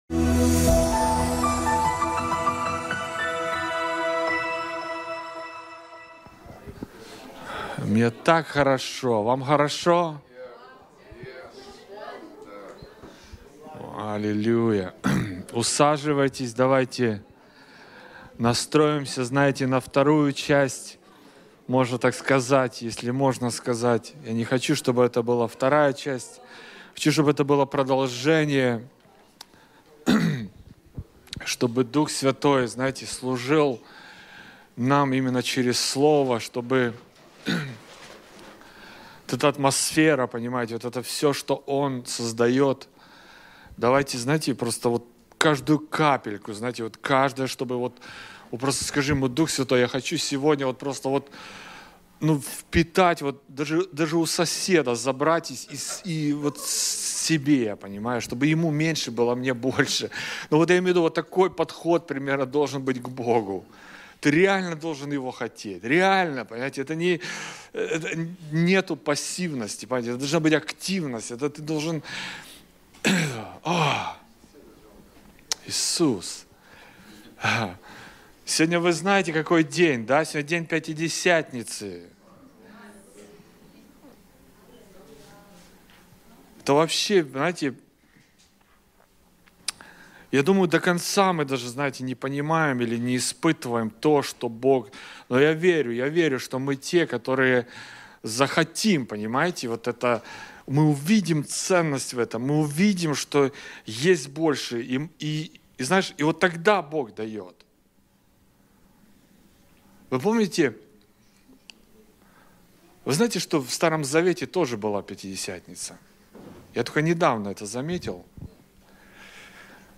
Категория : Другие проповедники в TCCI